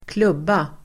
Uttal: [²kl'ub:a]